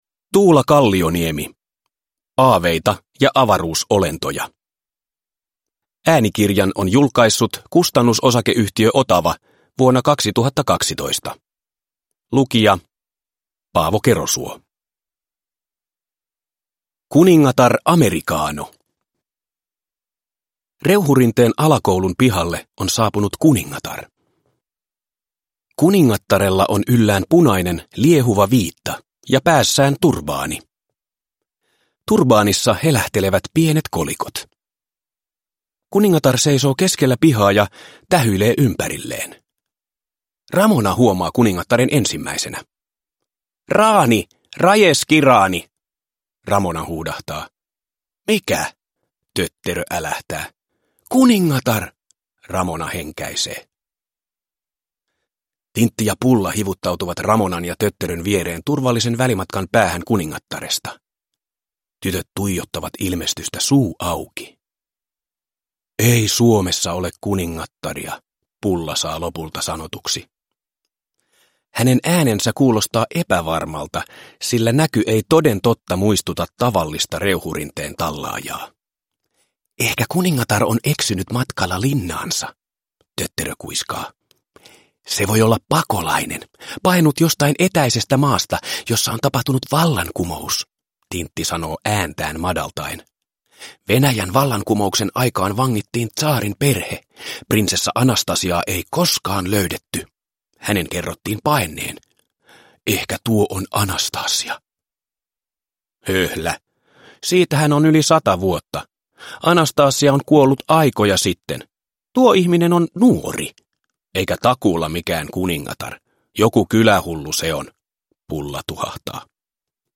Aaveita ja avaruusolentoja – Ljudbok – Laddas ner